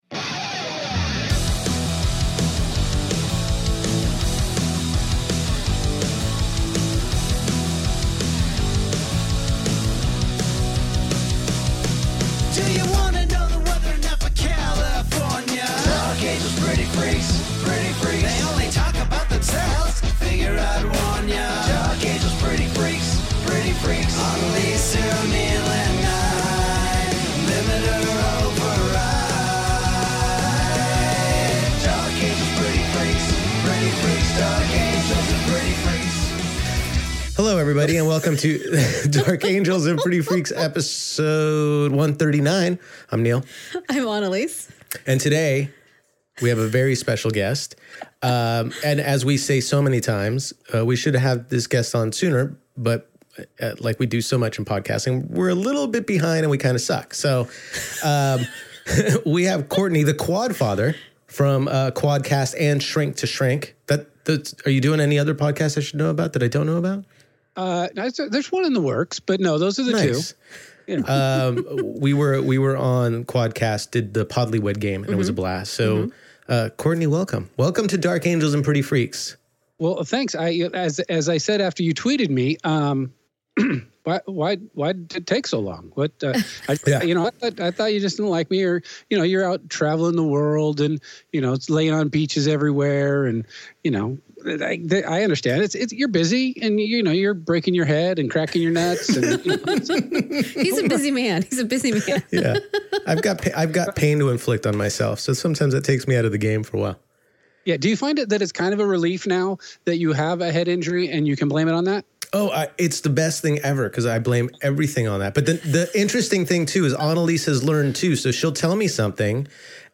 A fun conversation and a great time. We chat about Movies, Fantasy, Family, Ourselves, 5 Favorite Holiday Traditions and so much more!